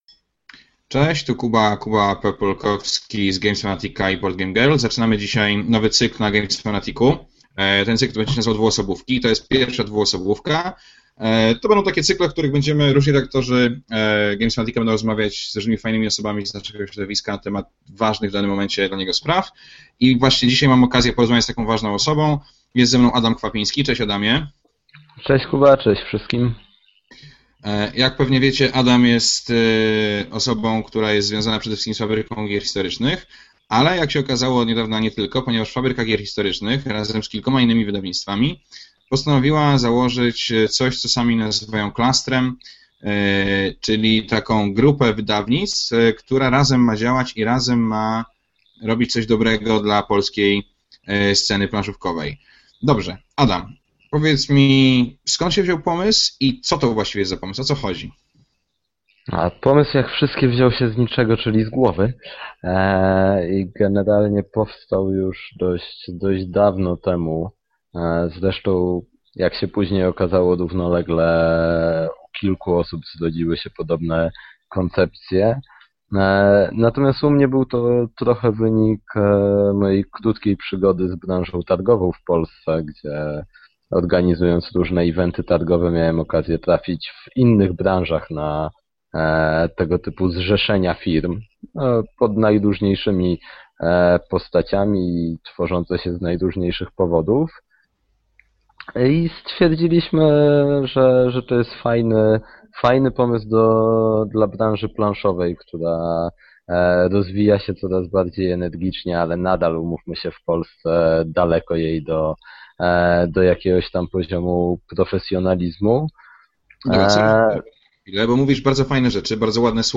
Dzisiaj zaczynamy nowy, zupełnie nieregularny cykl na Games Fanatic. Dwuosobówki, bo tak się cykl będzie nazywał, to spotkania z interesującymi czy ważnymi dla planszówkowego świata osobami, prowadzone w formule „jeden na jeden”.
Wywiad w formie dźwiękowej (mp3):